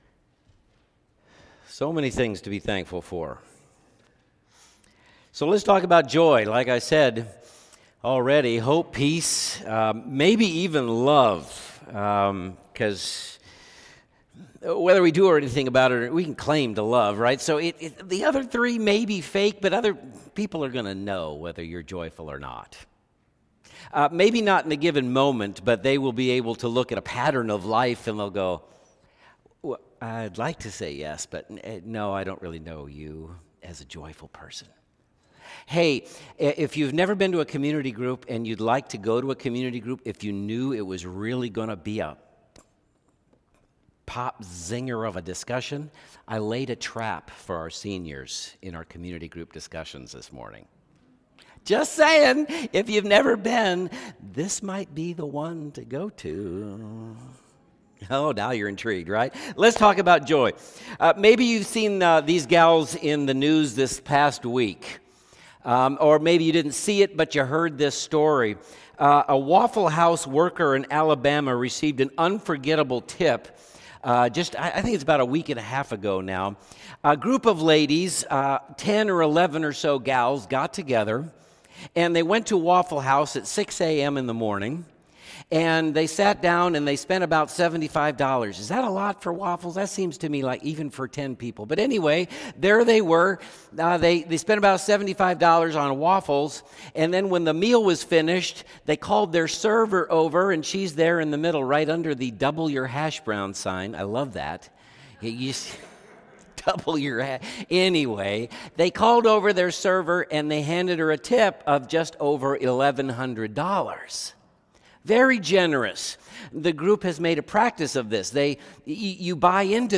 Sermons | Converge Church